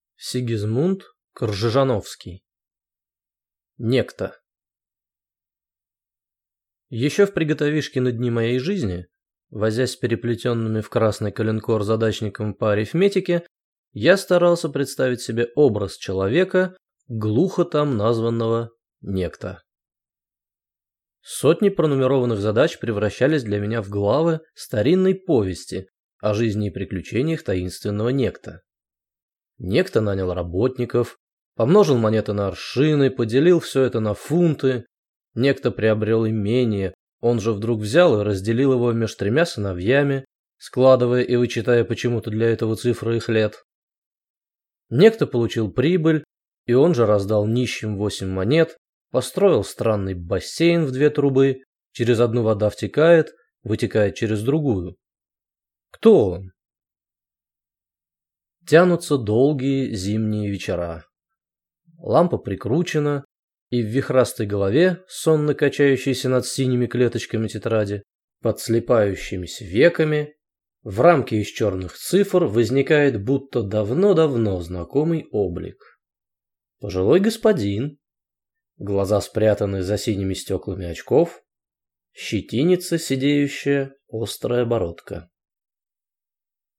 Аудиокнига «Некто» | Библиотека аудиокниг